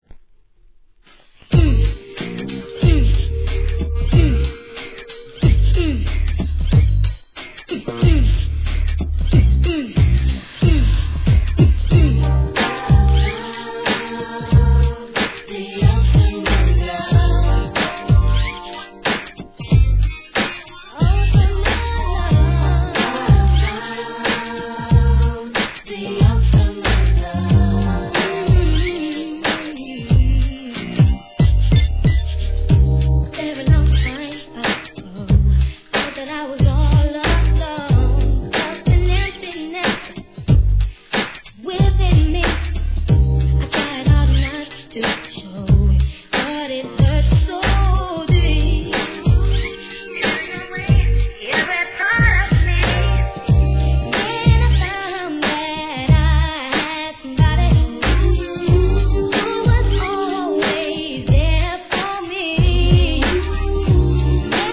HIP HOP/R&B
TALK BOXフックがメロ〜なSUMMERチュ〜ン!!